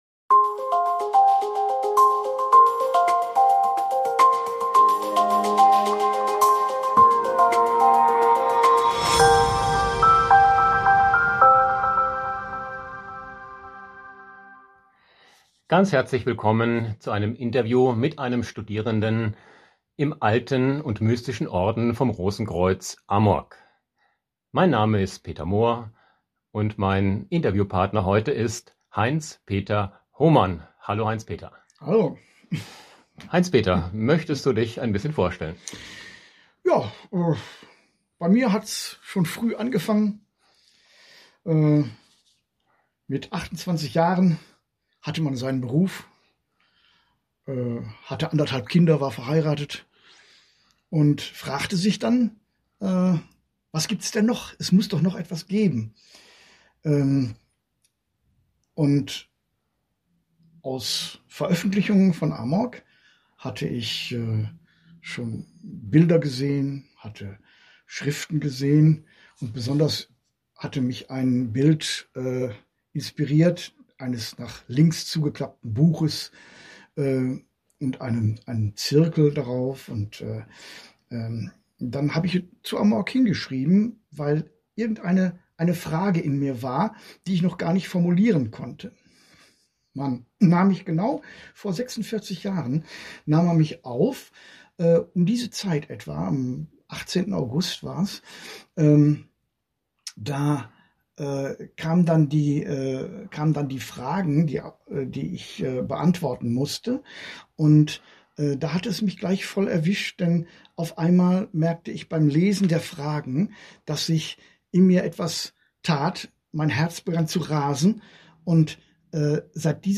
Rosenkreuzer im Gespräch